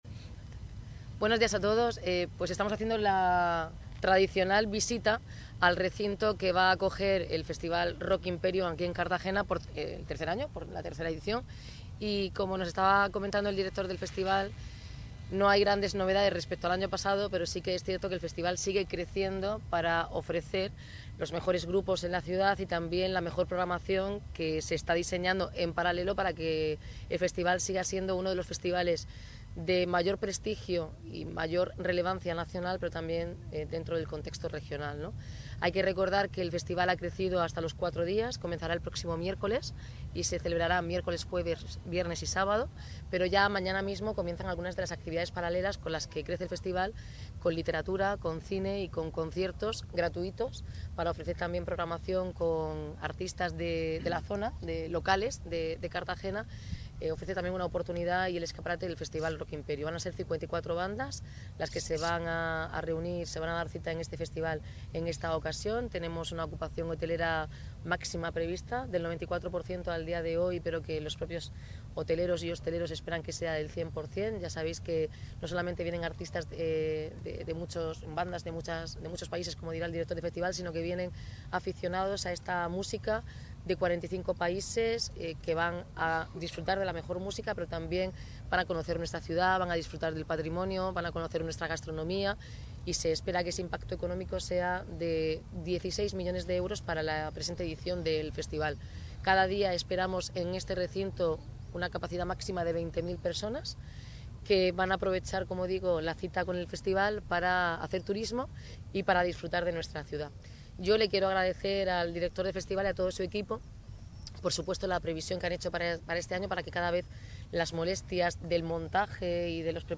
Audio: Declaraciones de Noelia Arroyo